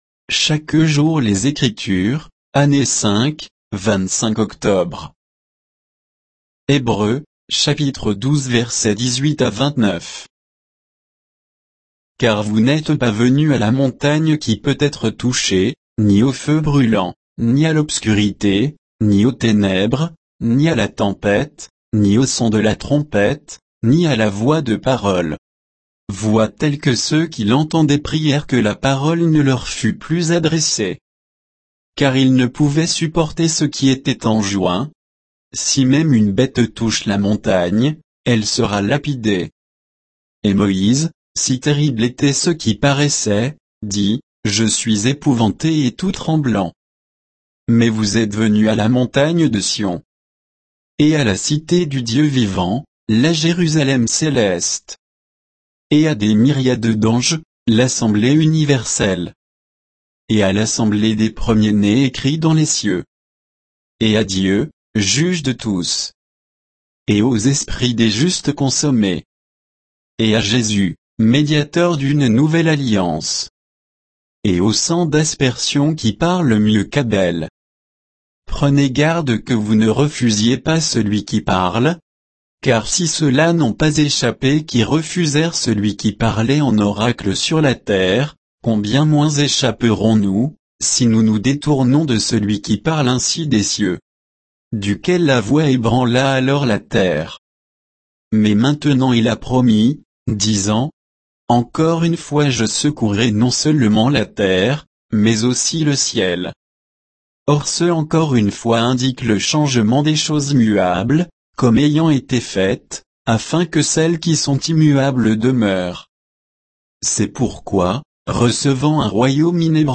Méditation quoditienne de Chaque jour les Écritures sur Hébreux 12, 18 à 29